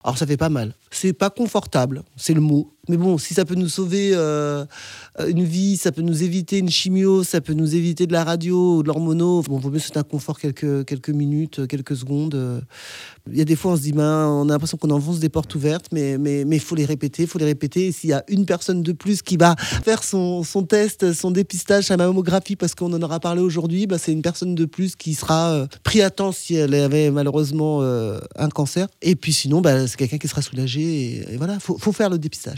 Au micro d'ODS radio, Frédérique Bangué invite chacune à agir pour sa santé et celle de ses proches. À travers son témoignage, la Haute-Savoyarde encourage toutes les femmes à se faire dépister.